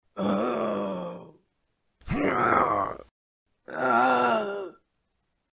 Звуки кряхтения
Кряхтение похожее на стон